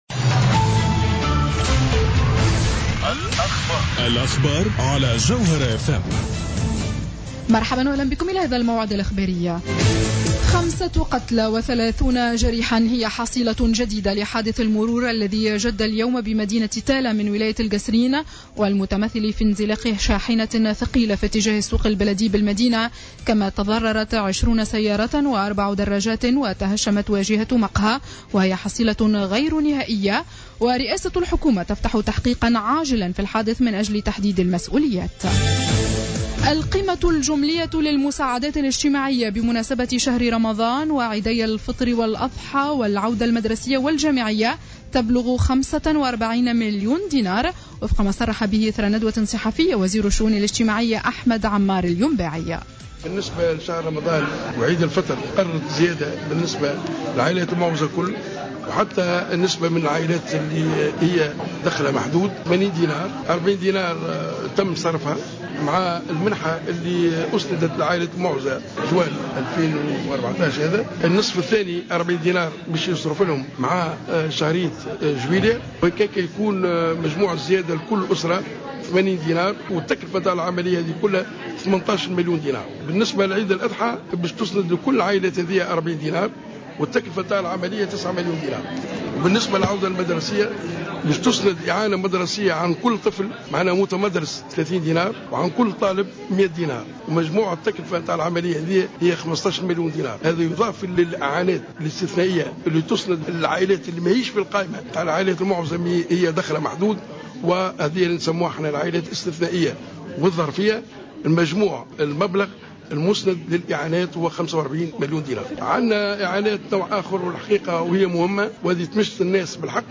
نشرة أخبار السابعة مساء ليوم الخميس 26-06-14